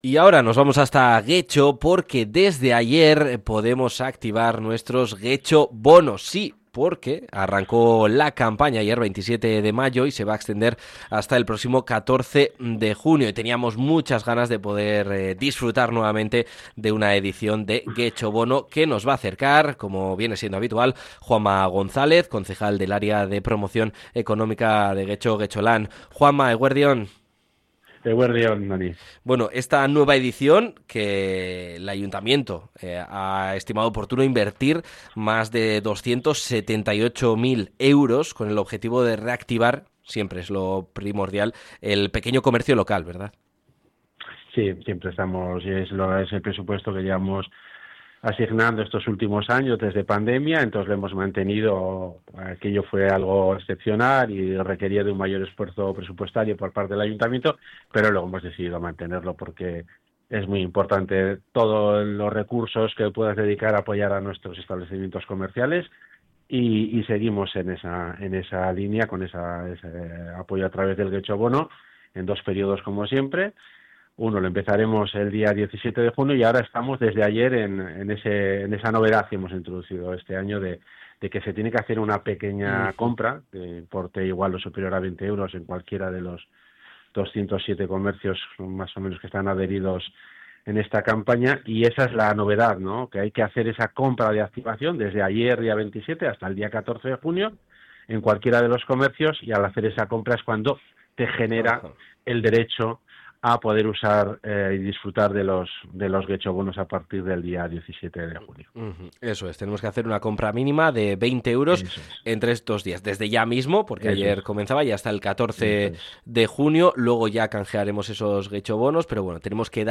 Juanma González, concejal de Promoción Económica-Getxolan, nos detalla esta nueva edición de GetxoBono